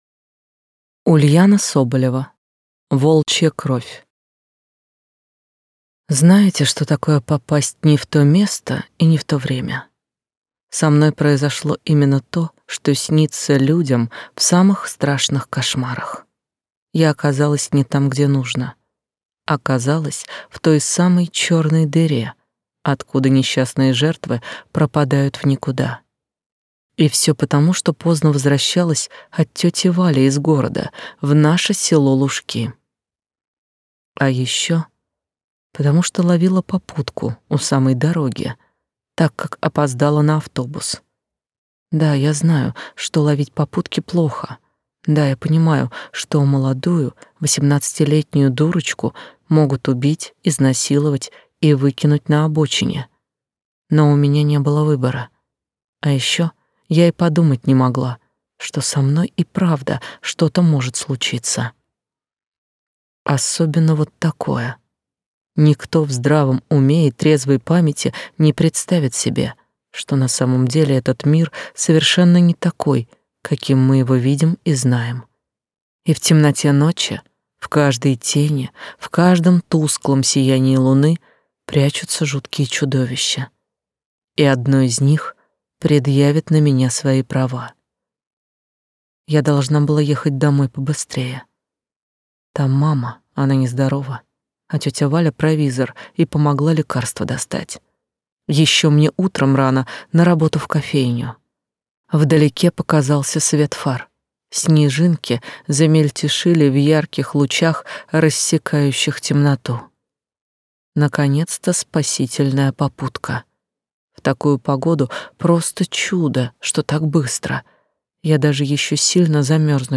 Аудиокнига Волчья кровь | Библиотека аудиокниг